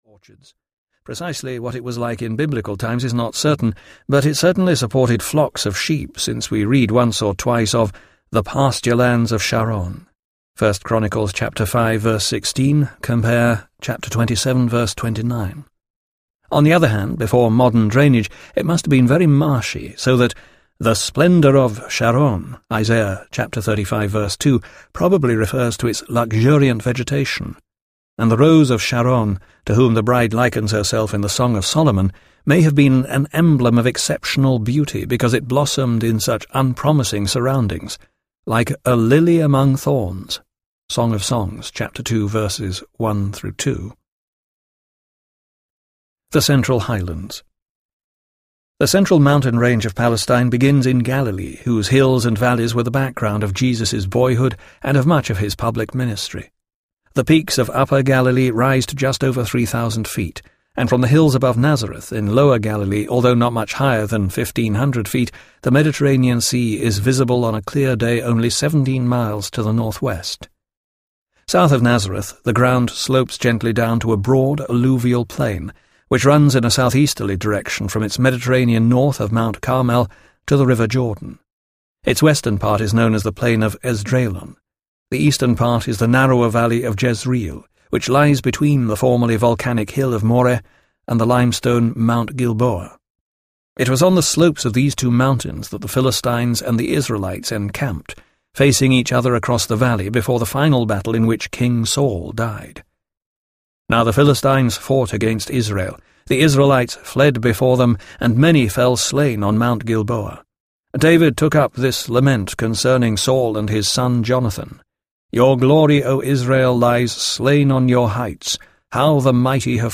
Understanding the Bible Audiobook
Narrator
7.45 Hrs. – Unabridged